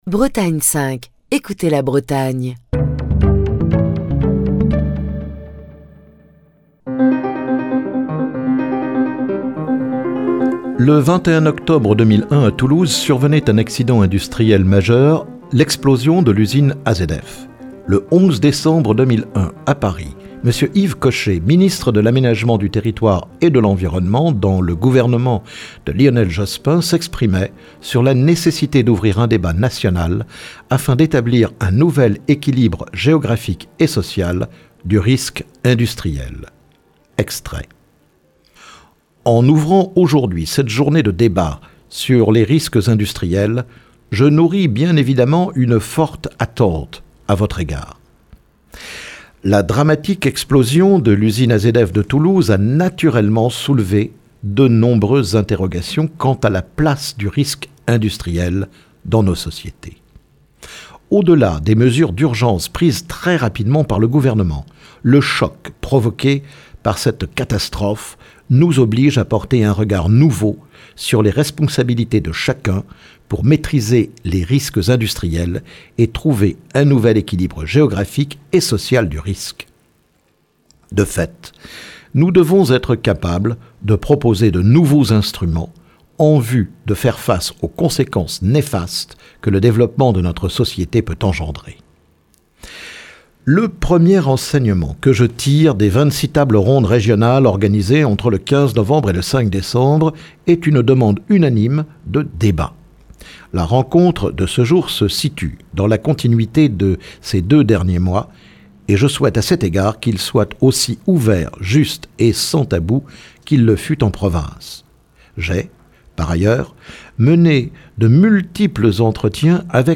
Le 21 septembre 2001 à Toulouse survenait un accident industriel majeur : L’explosion de l’usine AZF. Le 11 décembre 2001 à Paris M. Yves Cochet, ministre de l'aménagement du territoire et de l'environnement dans le gouvernement de Lionel Jospin, s’exprimait sur la nécessité d'ouvrir un débat national afin d’établir un nouvel équilibre géographique et social du risque industriel.